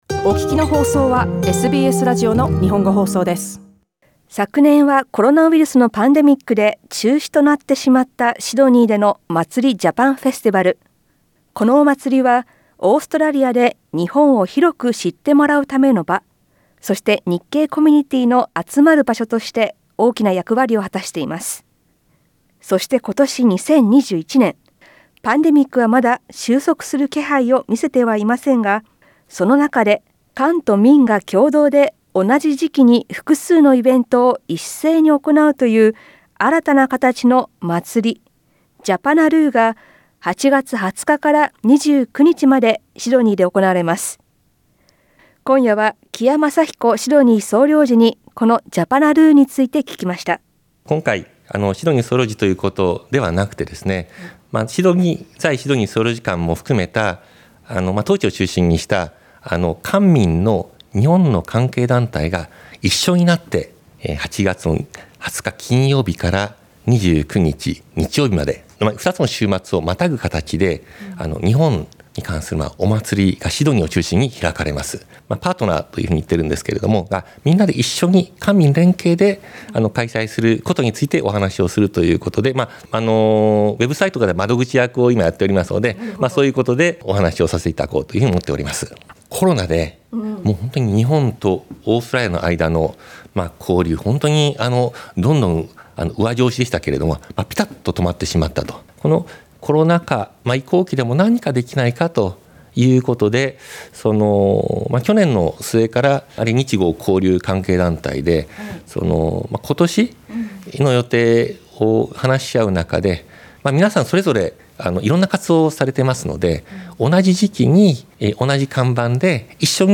紀谷昌彦・シドニー総領事に、10日間にわたって行われる「Japanaroo（ジャパナルー）」について聞きました。